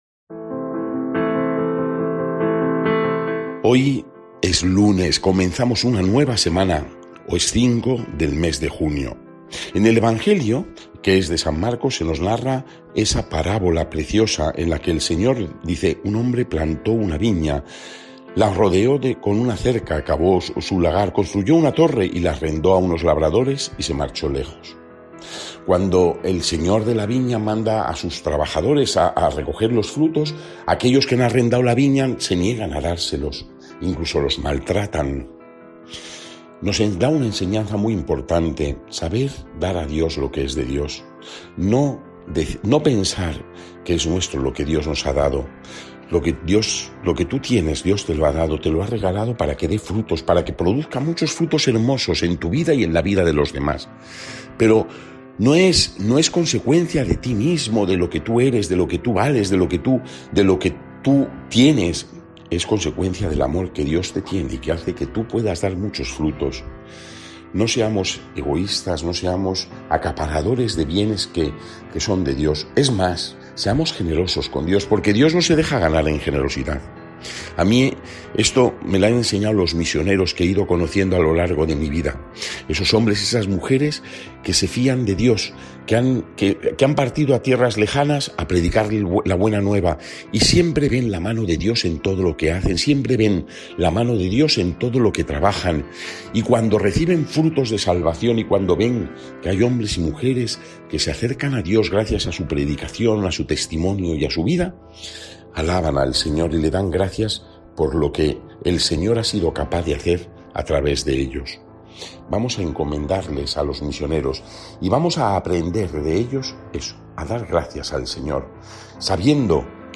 Evangelio del día
Lectura del santo evangelio según san Marcos 12,1-12